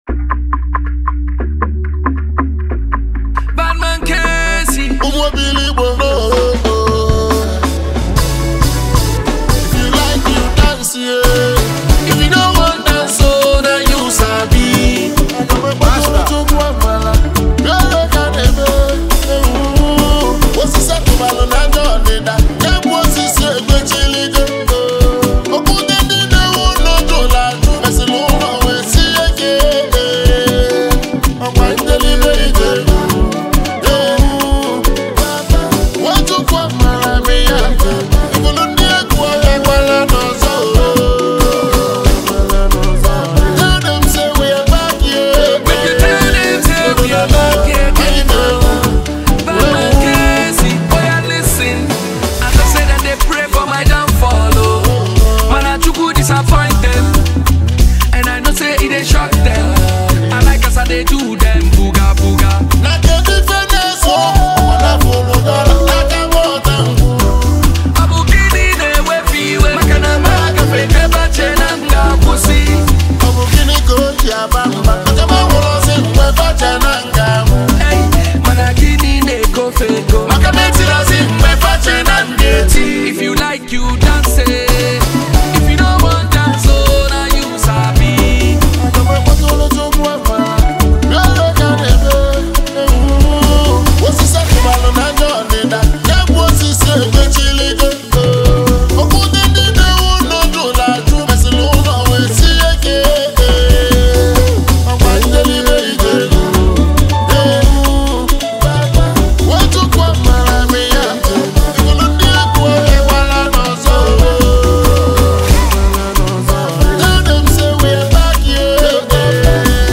feel-good energy and irresistible rhythm